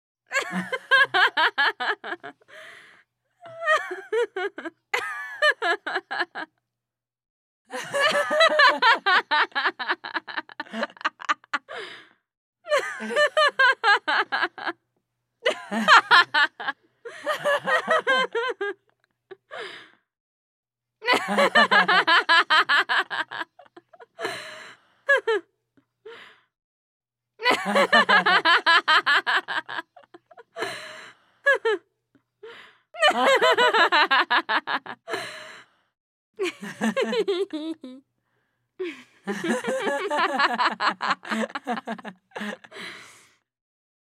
Звуки женского смеха
Звук смеха двух девушек